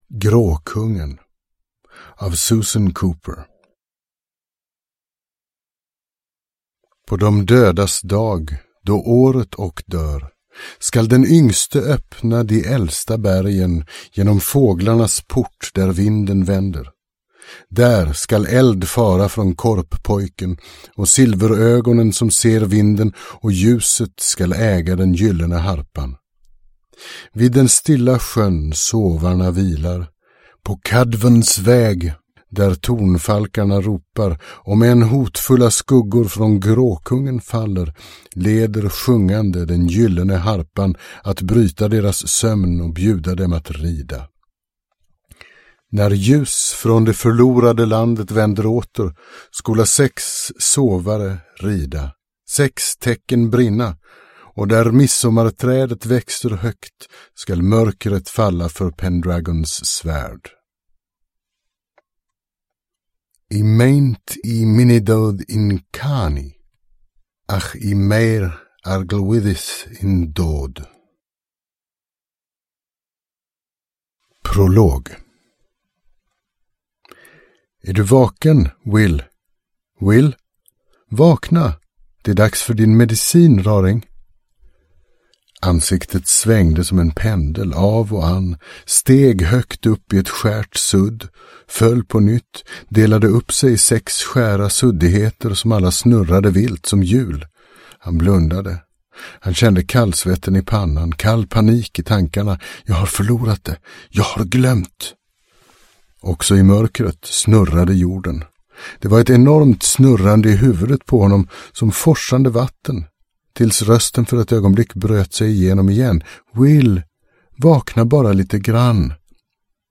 Gråkungen – Ljudbok – Laddas ner